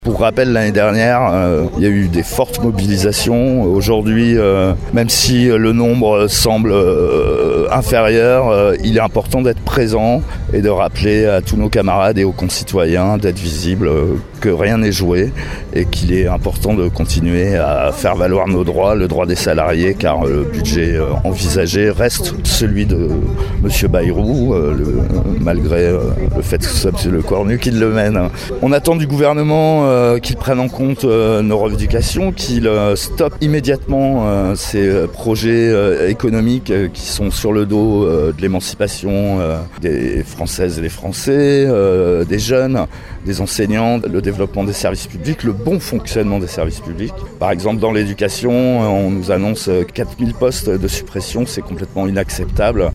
Manifestation hier au square Trivier à Rochefort.